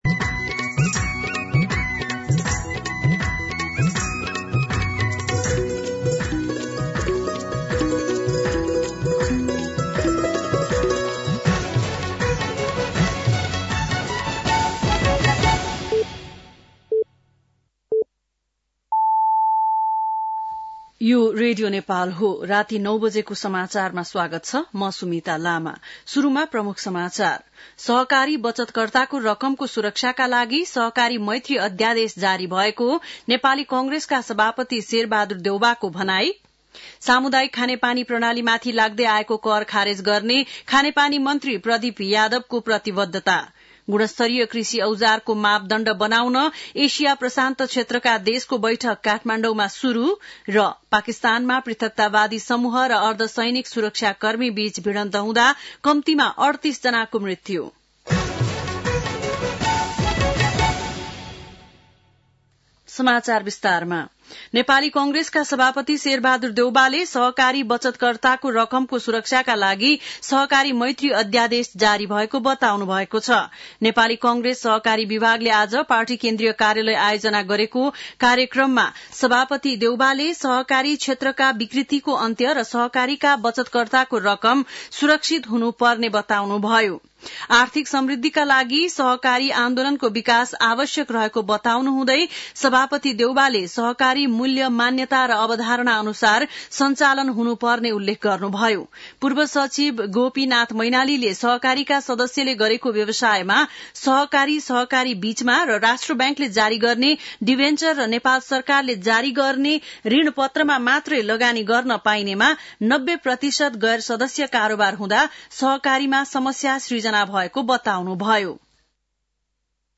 बेलुकी ९ बजेको नेपाली समाचार : २० माघ , २०८१
9-PM-Nepali-News-10-19.mp3